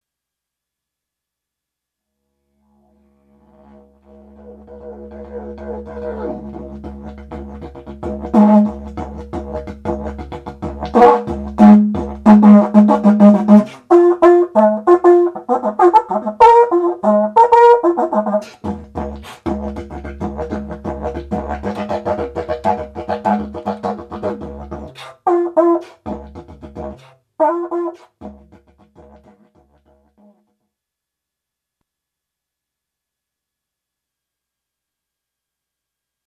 Didg/horn
Two instruments wrapped up in one. This swirl is a didgeridoo and a bugle like horn twisted together.